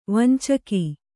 ♪ vancaki